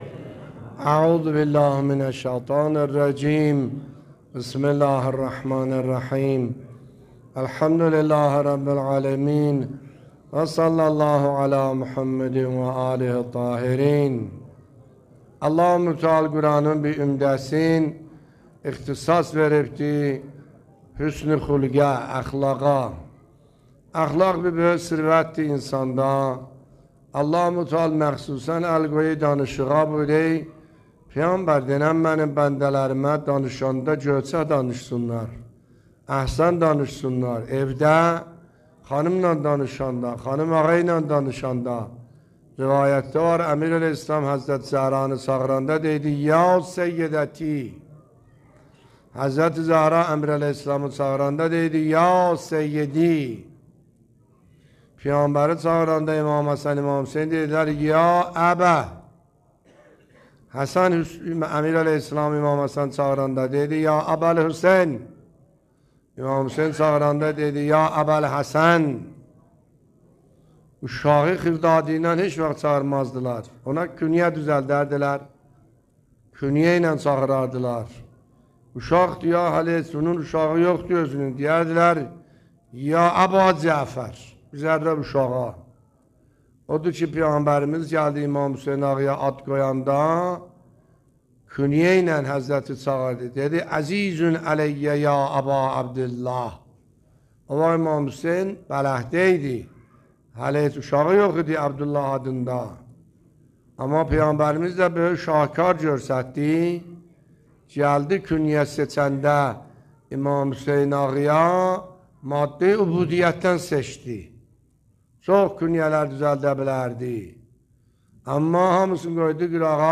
نکات برگزیده تفسیری آیه 53 سوره مبارکه اسراء در بیان دلنشین آیت الله سید حسن عاملی در مسجد مرحوم میرزا علی اکبر در هشتمین شب رمضان المبارک 1402 به مدت 22 دقیقه